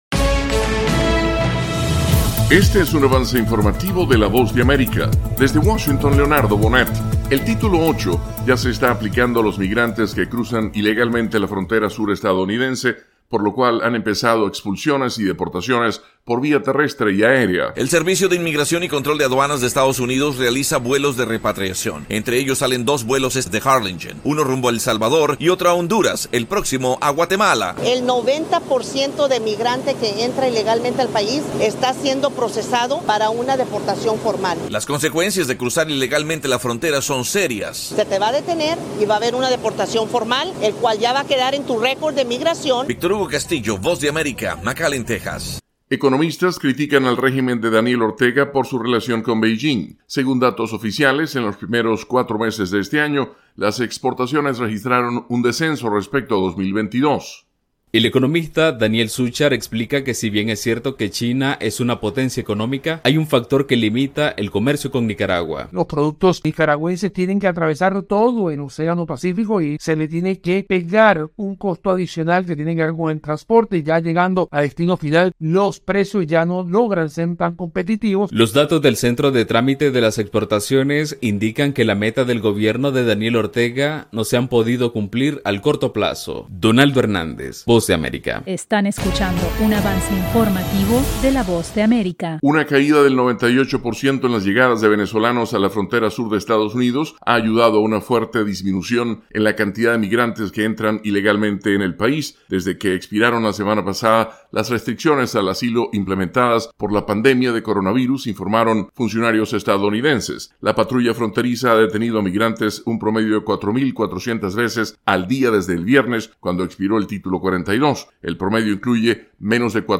Avance Informativo 7:00 PM